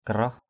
/ka-rɔh/ (đg.) gọt = émincer. karaoh rabung k_r<H rb~/ gọt măng = émincer une pousse de bambou.